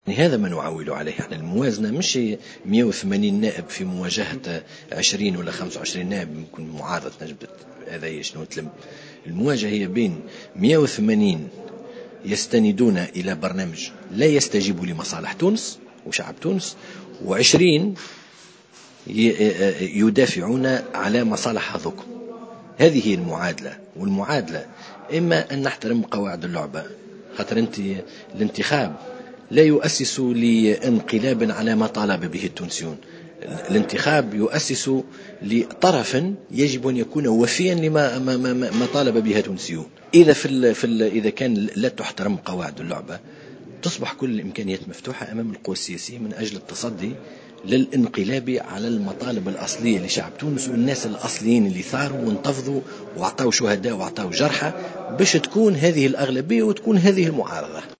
وقال لخضر في حوار مع "الجوهرة أف أم"